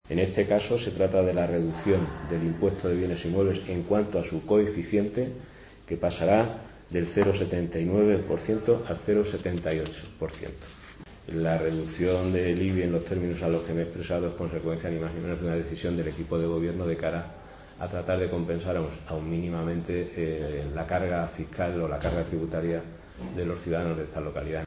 El portavoz de Gobierno, Francisco Delgado, ha señalado que “se trata de la reducción del IBI en cuanto a su coeficiente, que pasará del 0,79% al 0,78%, una reducción que es consecuencia de una decisión del equipo de Gobierno de cara a compensar mínimamente la carga fiscal de los ciudadanos de la localidad”.